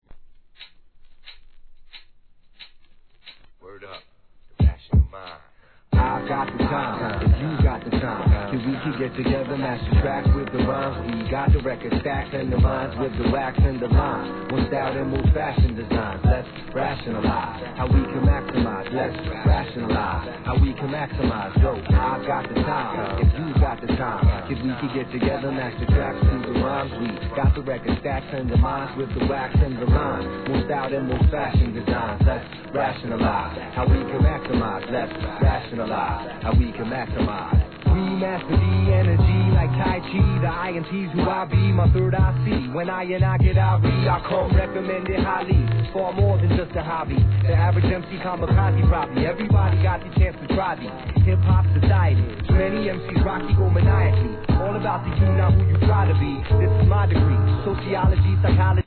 HIP HOP/R&B
*チリチリはSAMPLING音源の物です